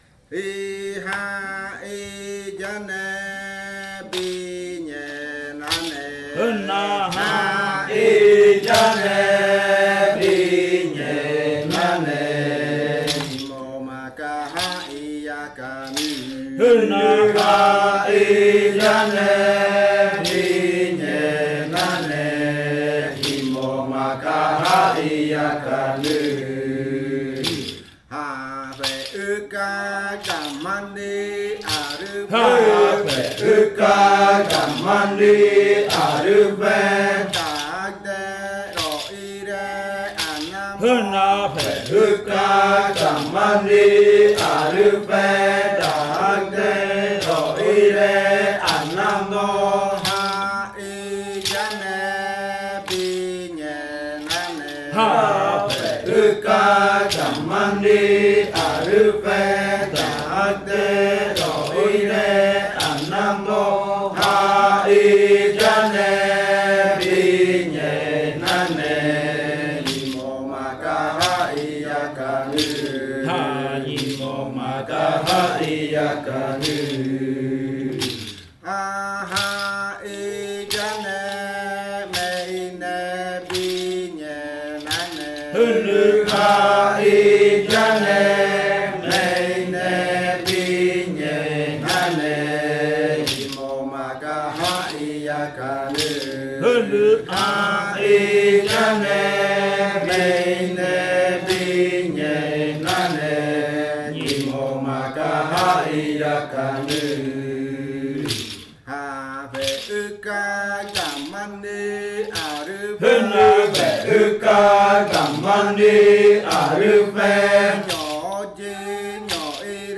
Canto de amanecida de la variente jimokɨ
con el grupo de cantores sentado en Nokaido. Este canto hace parte de la colección de cantos del ritual yuakɨ murui-muina (ritual de frutas) del pueblo murui, colección que fue hecha por el Grupo de Danza Kaɨ Komuiya Uai con apoyo de la UNAL, sede Amazonia.
with the group of singers seated in Nokaido.